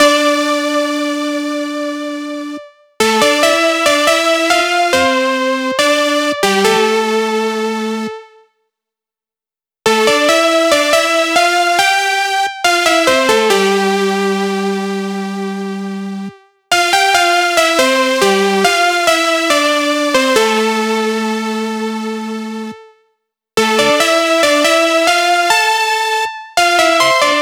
VTS1 25 Kit Melody & Synth